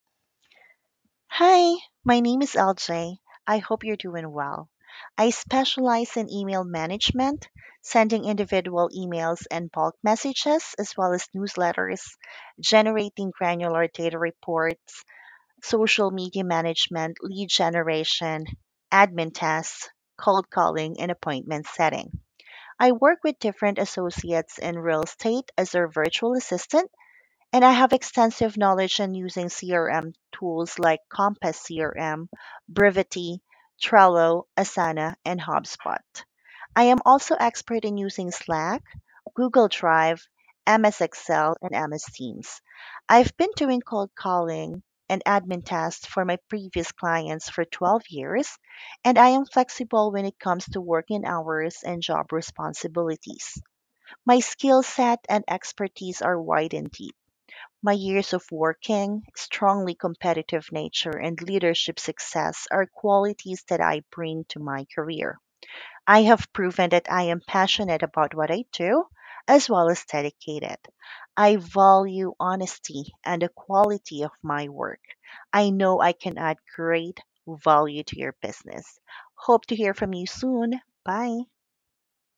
Self Introduction